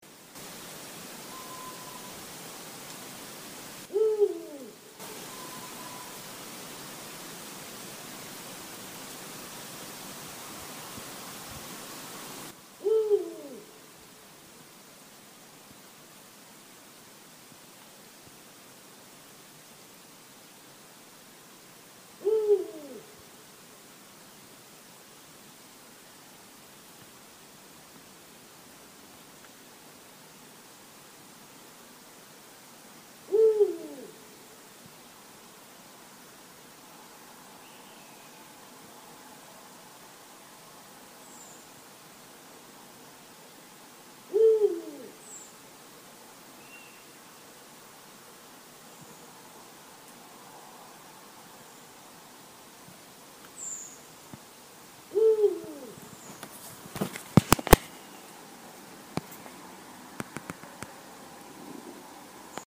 Owl #2